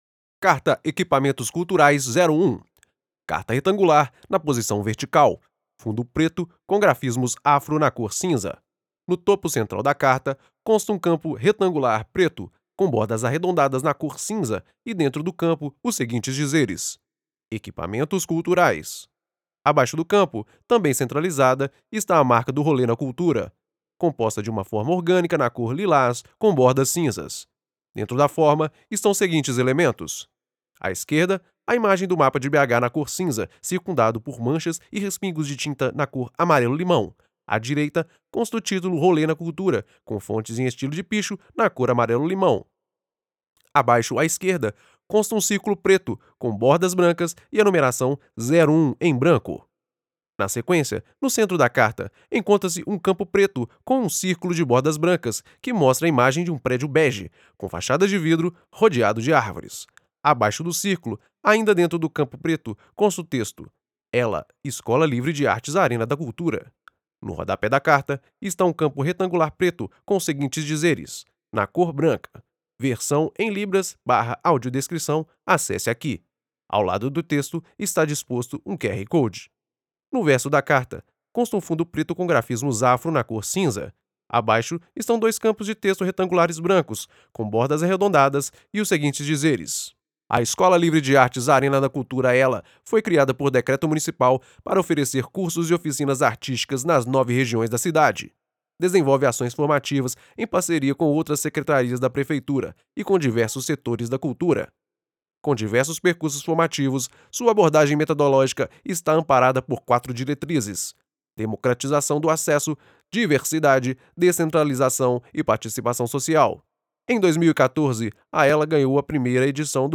Audiodescrição ELA